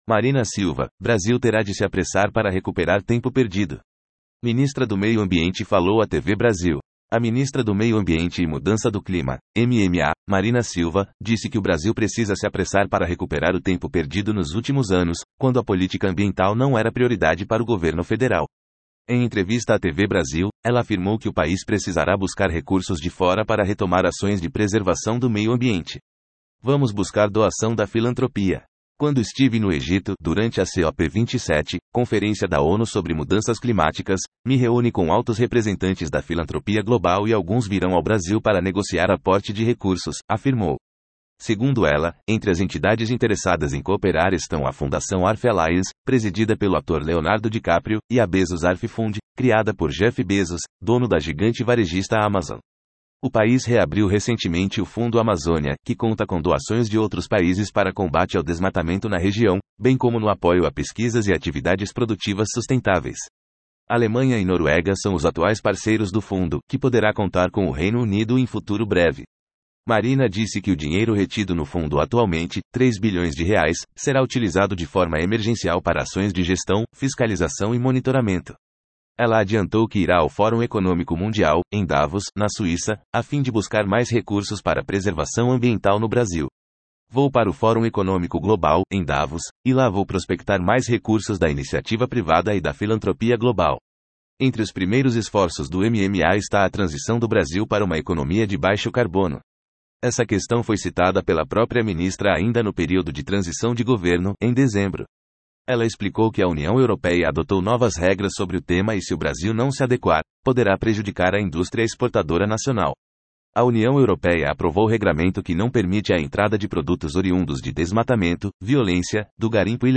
Ministra do Meio Ambiente falou à TV Brasil